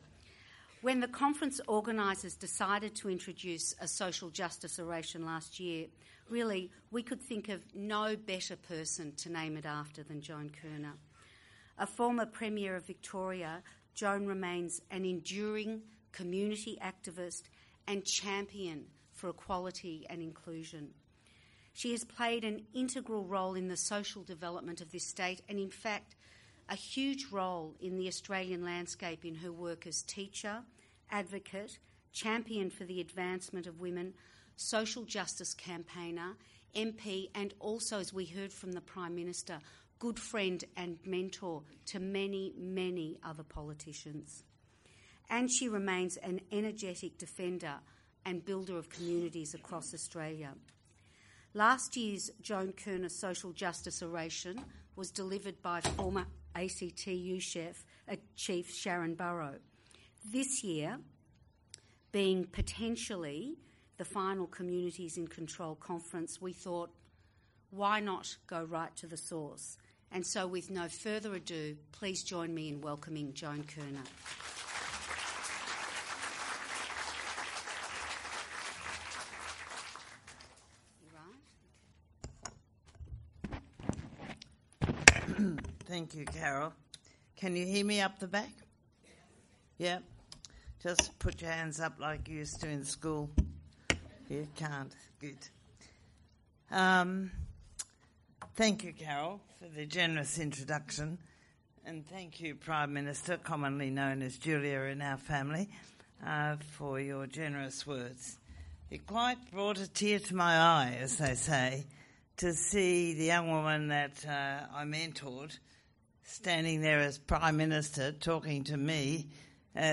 The 2012 Joan Kirner Social Justice Oration
In this keynote, the Hon Joan Kirner AC builds on last year's Social Justice Oration by former ACTU chief and international labour leader Sharan Burrow, and reflects on what she has learned over four decades of putting communities firmly in control - and where she thinks we need to go next.